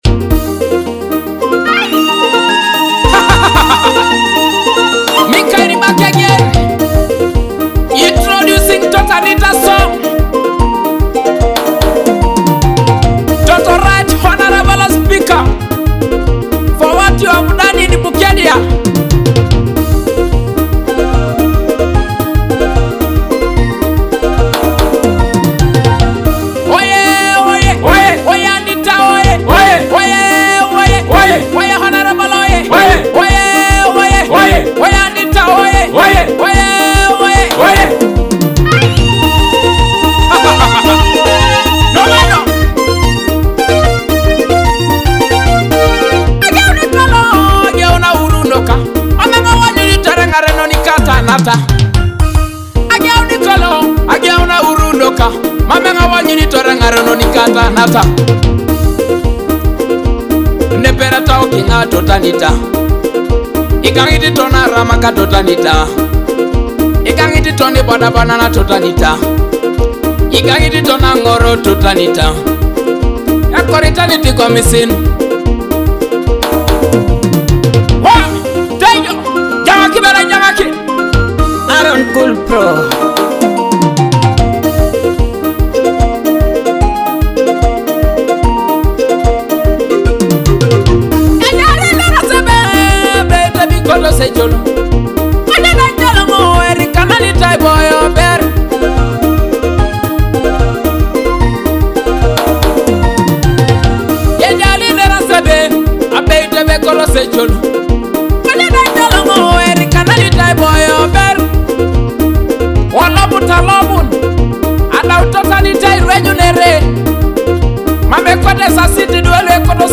With moving lyrics and an inspiring melody
Teso music